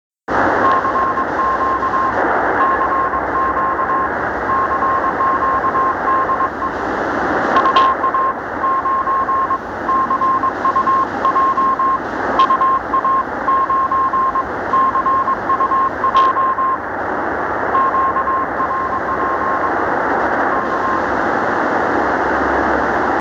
Сегодняшнее рандеву прослушивал на Tecsun PL-365 из МА-01, п/о, +20 °С, небольшой ветер.